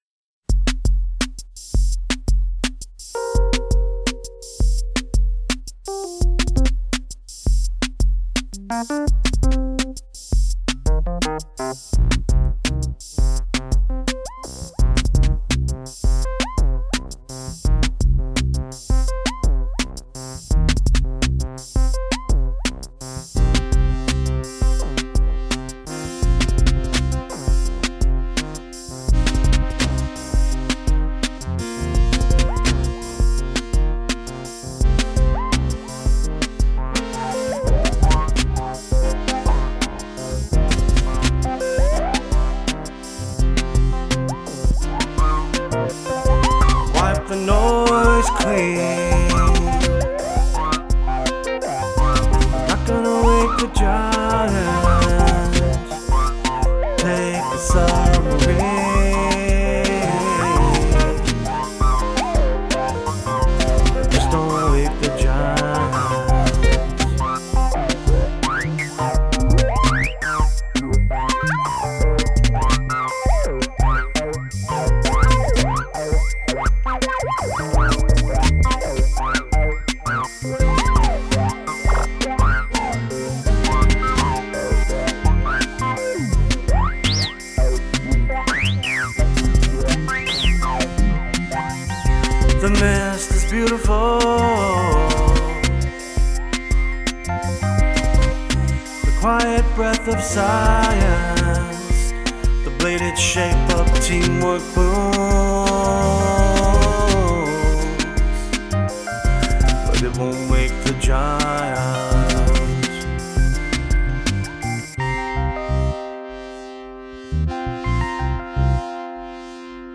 New tune recorded with Tascam 688
I wanted to use my analog synths (Korg Volca Bass and Korg Volca keys) and (analog) drum machine (Korg Volca Beats), along with my Tascam 688.
I didn’t bring enough equipment to do a proper mix, so this is just a rough one.
The repetitive drum tracks are just lifeless and one dimensional.
I played that with GarageBand's Wurli sound on my iPad.